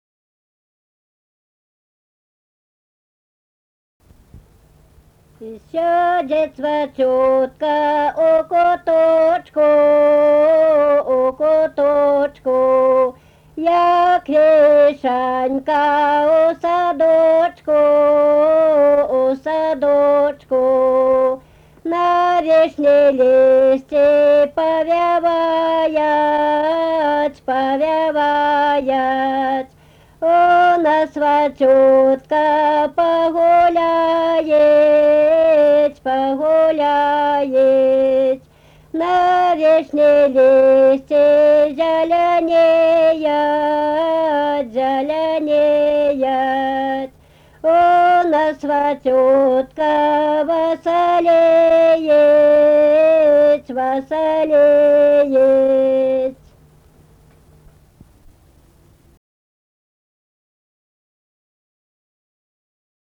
Kavaltiškė, Kavoliškės k.
Atlikimo pubūdis vokalinis
Baltarusiška daina